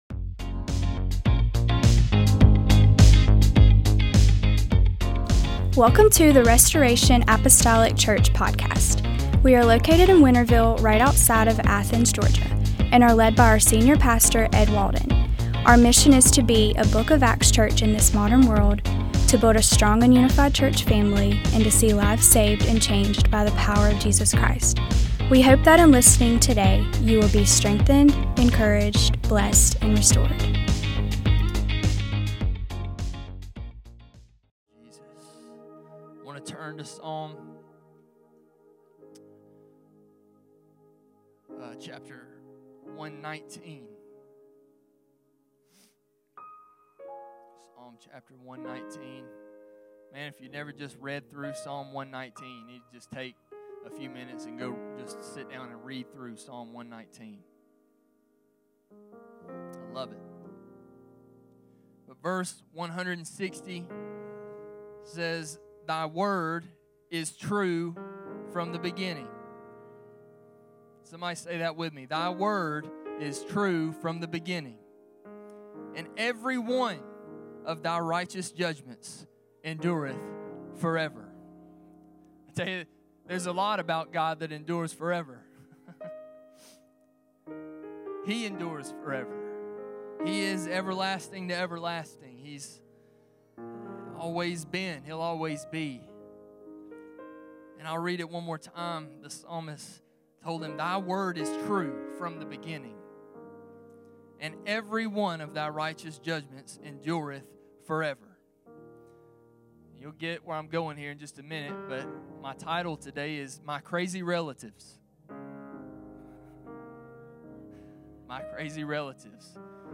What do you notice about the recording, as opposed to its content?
Sunday Service - 02/15/26 - Assist.